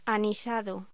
Locución: Anisado